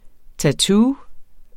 Udtale [ taˈtuː ]